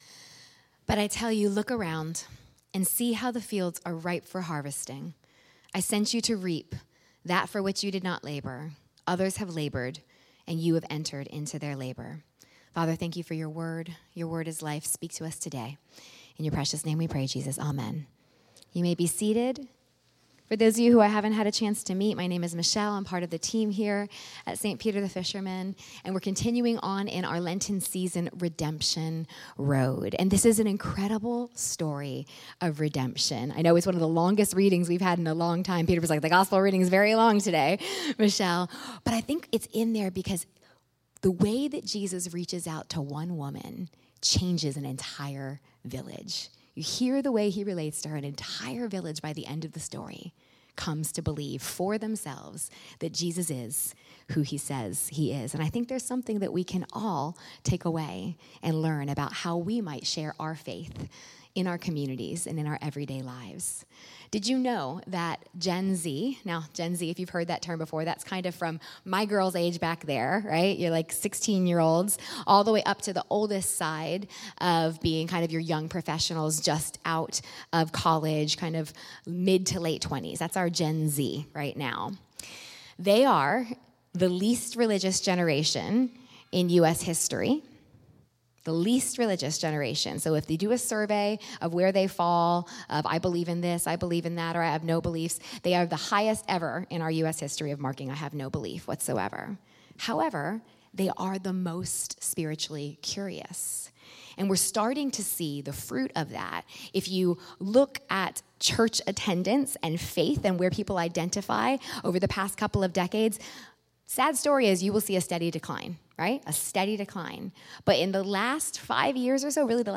Jesus conversation with the the woman at the well in Samaria tells us how to share our faith, naturally, sensitively, and with clarity. Sermon
St Peter the Fisherman, New Smyrna Beach, Florida.